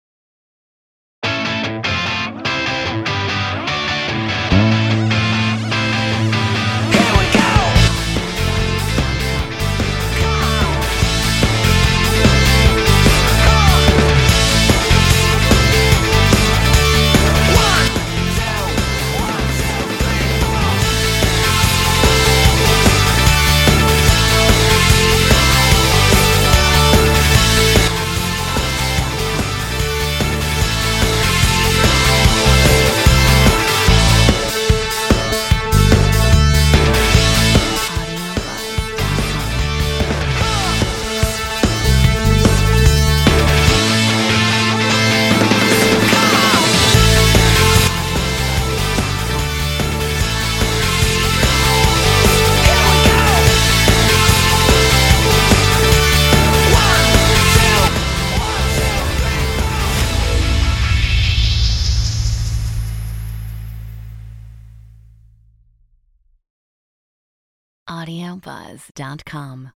Metronome 147